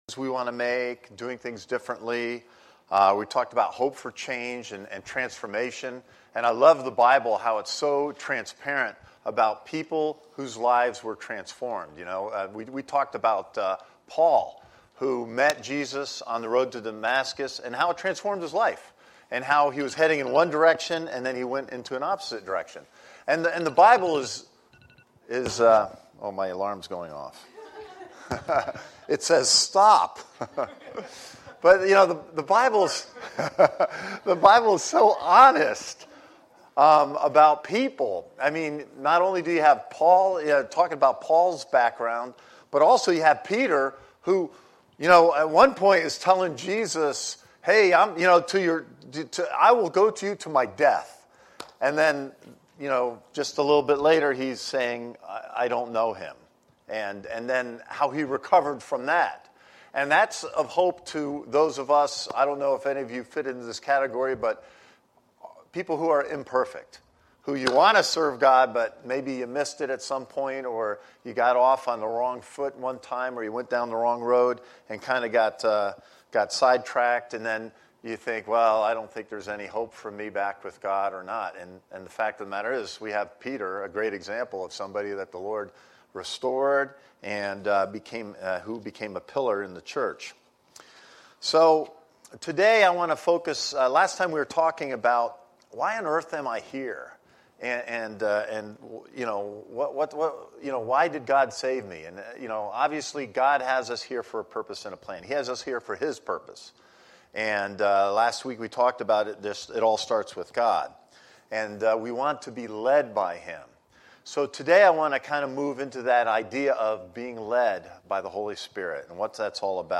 From Series: "Sunday Service"
This is the main Sunday Service for Christ Connection Church